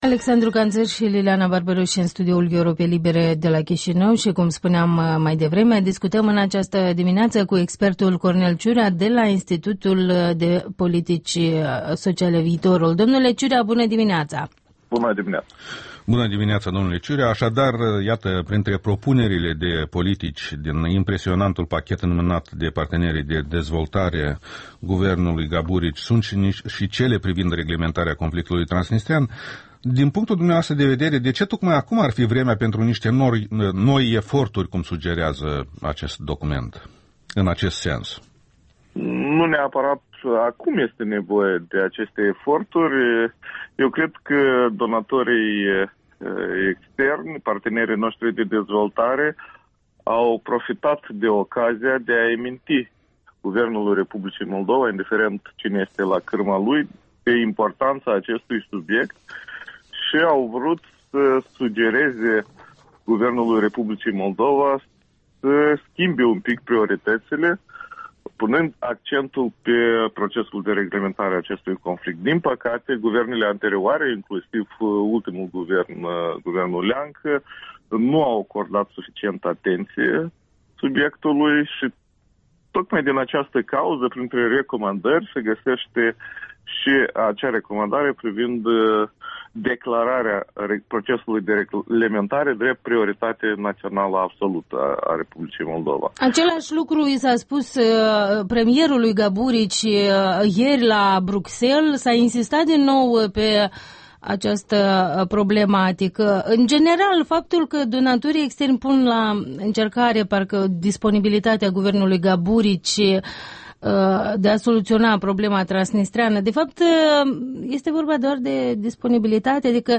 Interviul dimineții cu expertul Institutului IDIS „Viitorul”.